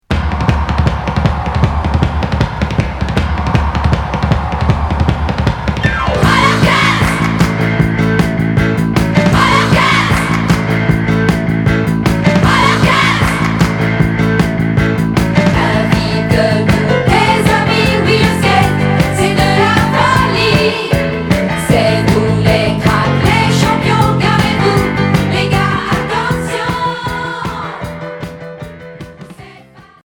Glam pop Unique 45t retour à l'accueil